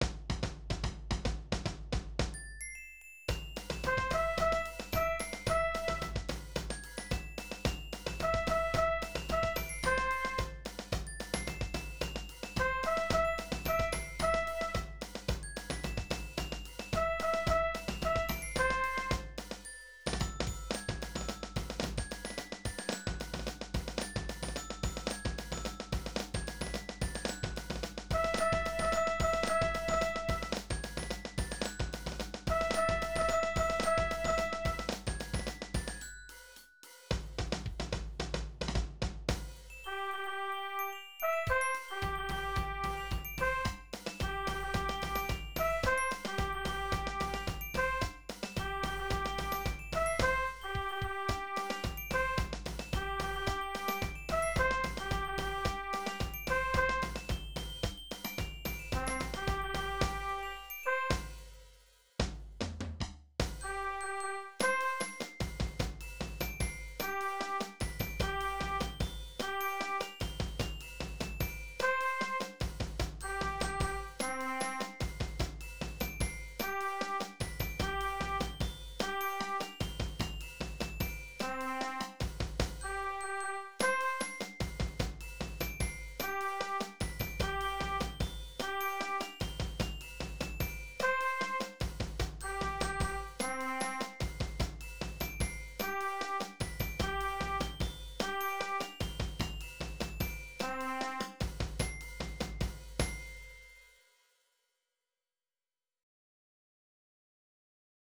Música para banda
Resumen : RESUMEN : Esta cartilla esta hecha para los formatos de banda marcial tradicional (lira, clarín, caja, granadera, tambora, bombo).
AnexoC_Popurri tambora.wav